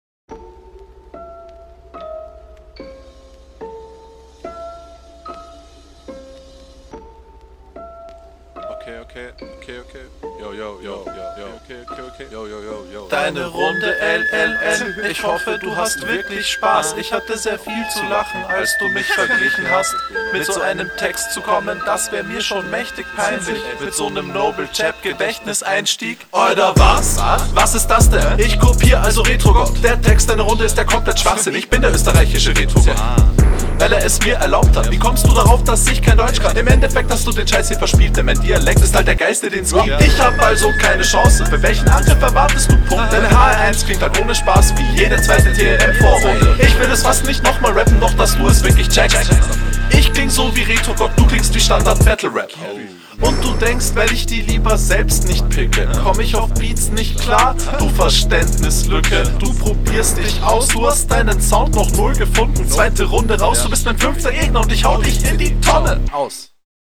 Deine Stimme ist strange, sag ich mal, ohne das böse zu meinen!